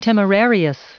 Prononciation du mot temerarious en anglais (fichier audio)